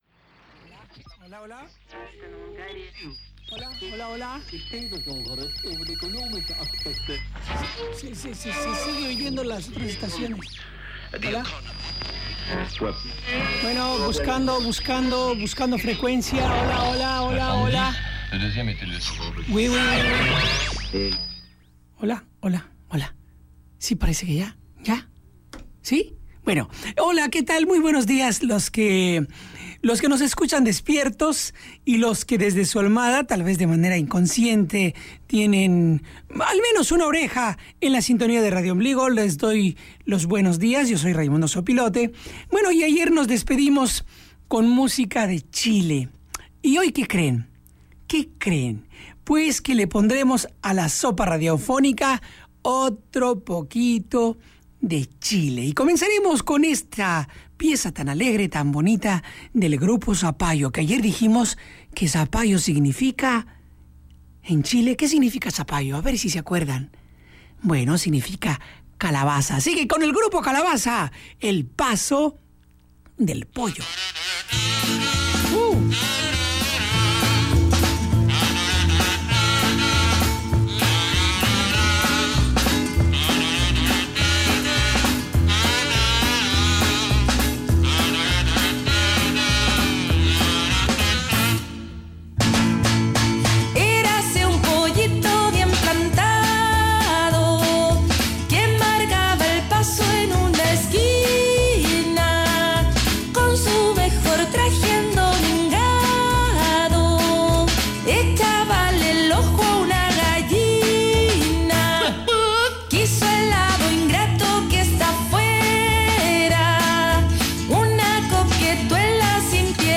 Para satisfacer tu deseo Radiombligo te ofrece más canciones